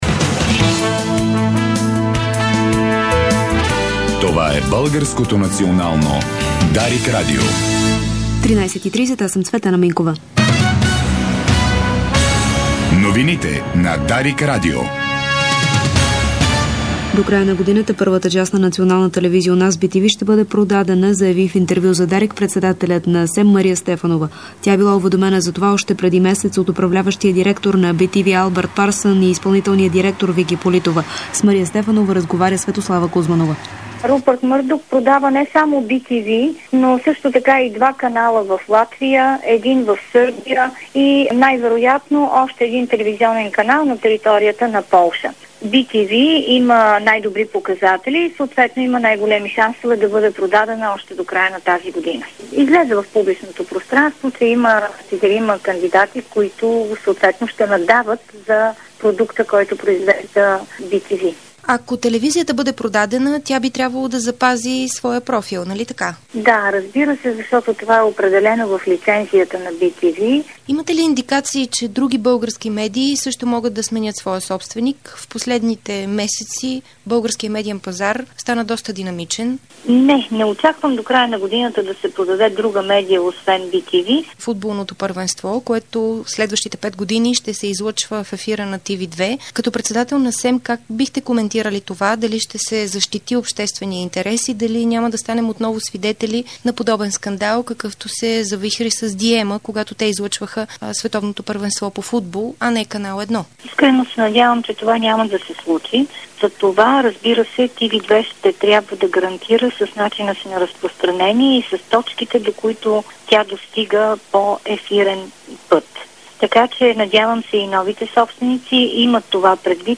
Обедна информационна емисия - 13.08.2008